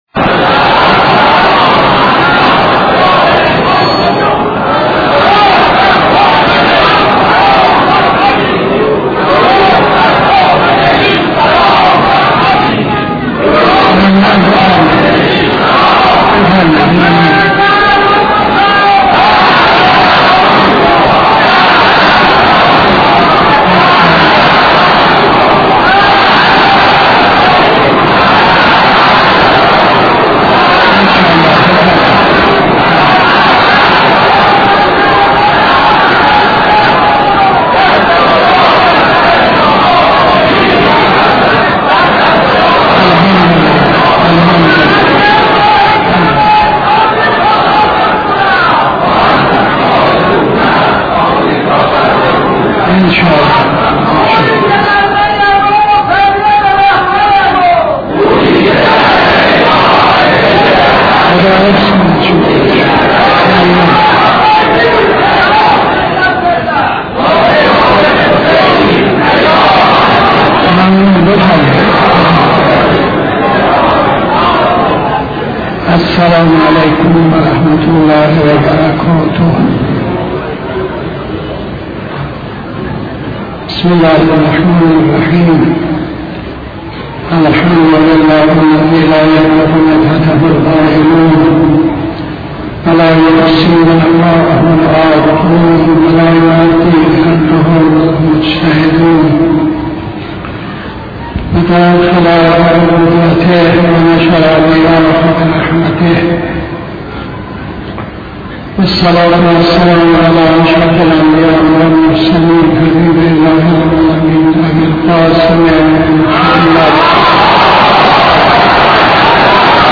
خطبه اول نماز جمعه 30-11-83